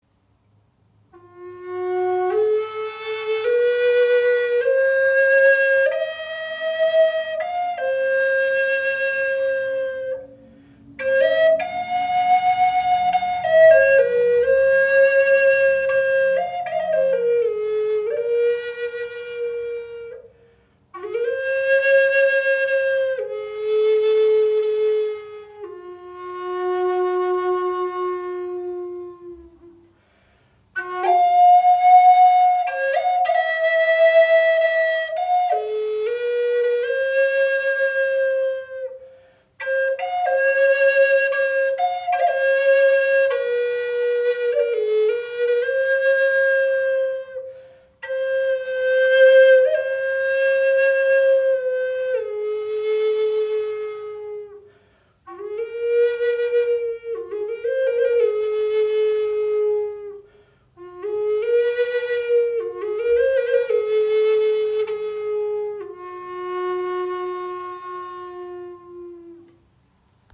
Diese 432 Hz Chakra Flöte in Fis-Moll aktiviert das Herzchakra und verbindet die heilende Herzfrequenz mit der klaren, warmen Stimme handgefertigten Walnussholzes.